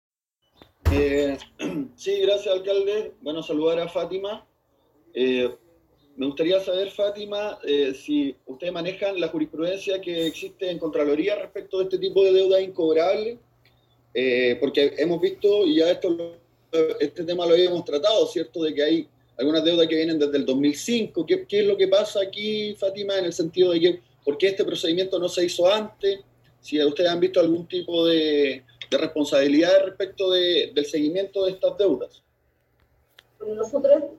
El primero en intervenir fue el Concejal Rodrigo Salazar quien consultó a la encargada del Departamento sobre si ese departamento maneja información respecto a la jurisprudencia que existe en contraloría respecto a este tema de las llamadas deudas incobrables ya que este tema se había tratado y existen deudas del año 2005 y de porque este procedimiento no se realizó antes y si existe algún tipo de seguimiento de estas deudas..
Salazar-intervencion.mp3